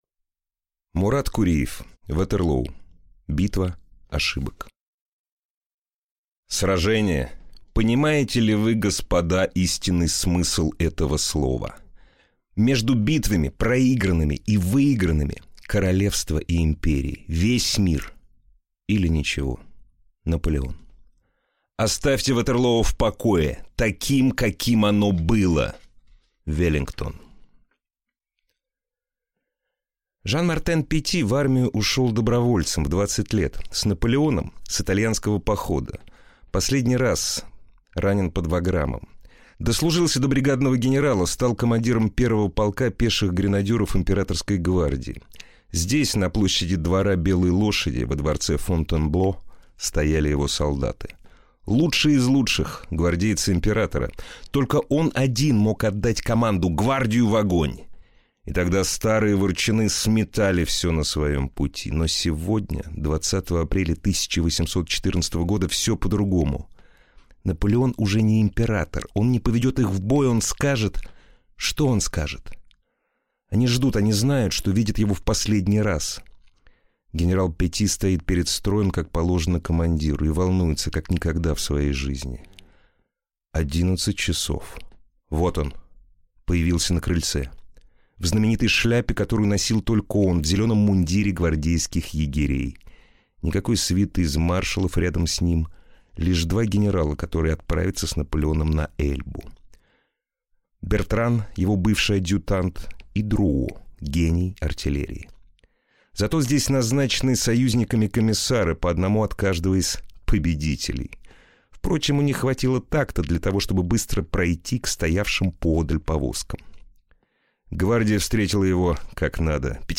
Aудиокнига Ватерлоо. Битва ошибок